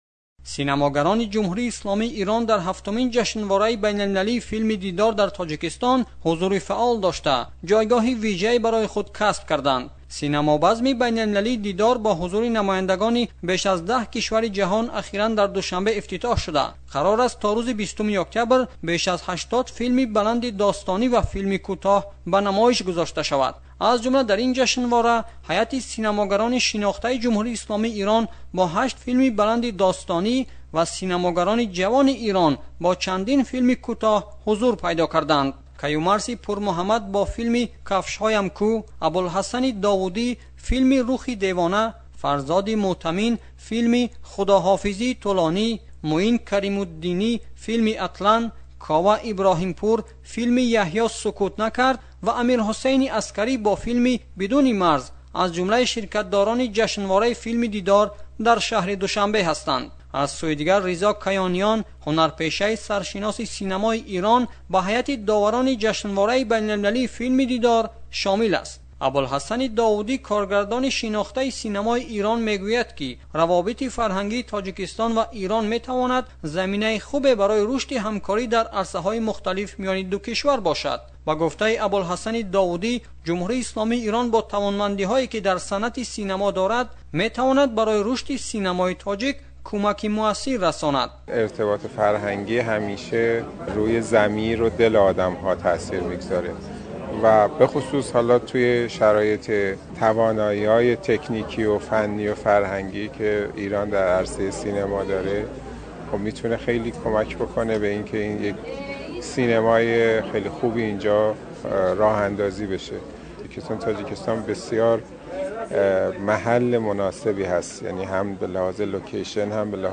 аз Душанбе гузориш медиҳад: